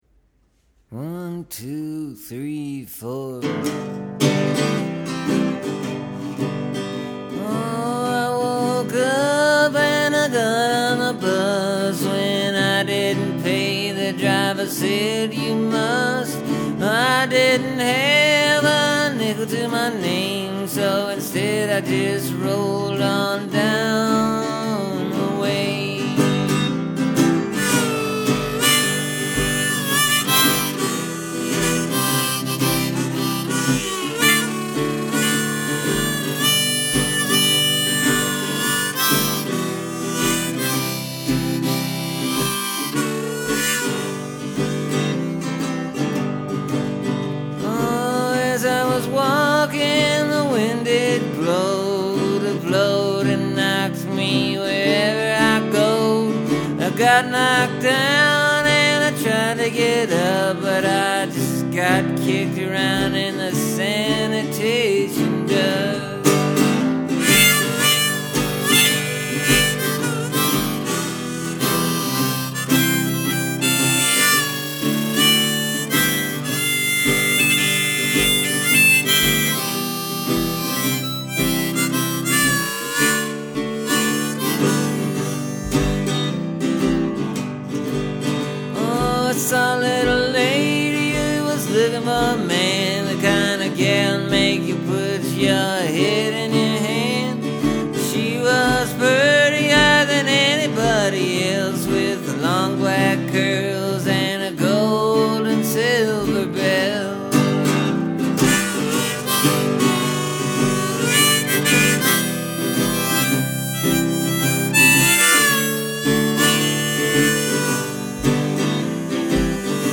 Other than it being a whole lot slower than the 2010 version, it’s also more of a ballad-type song instead of a blues-type song. There’s no repeating lines.
Kinda rolls along real easy. Not frantic and worried like the original.